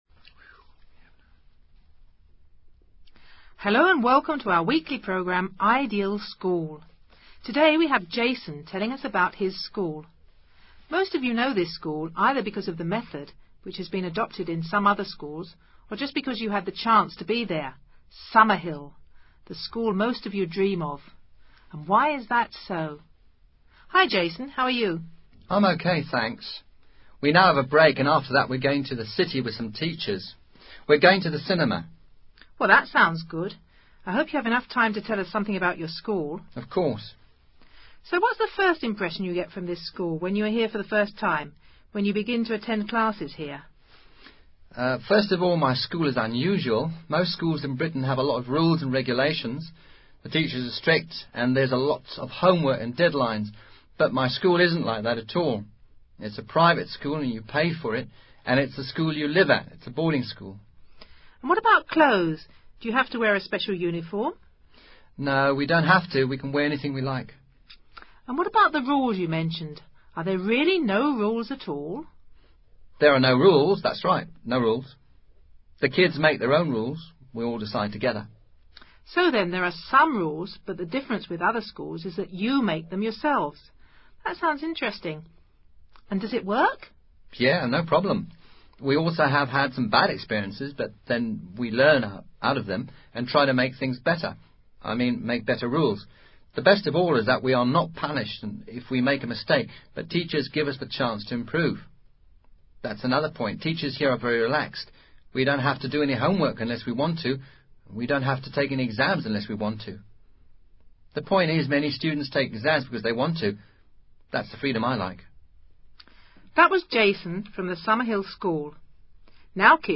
Un joven habla sobre su colegio. Útil para practicar relative clauses y distintos organizadores discursivos.